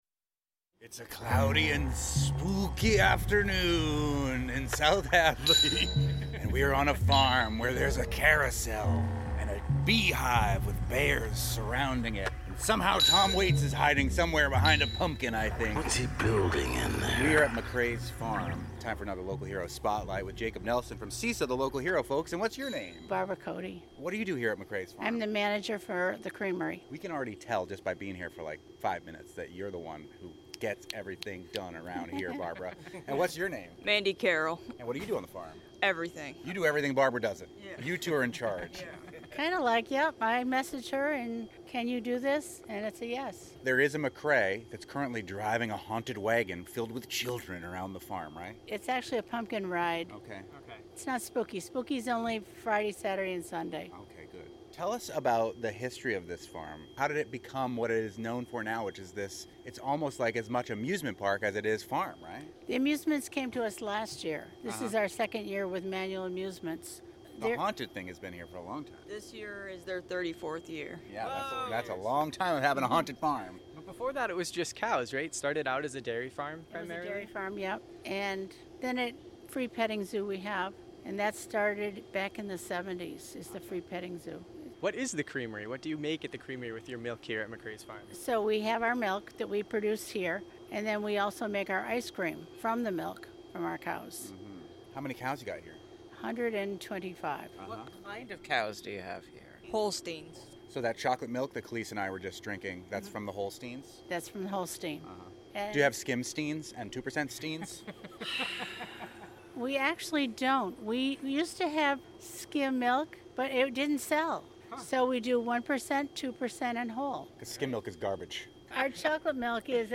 "Fabulous 413" interviews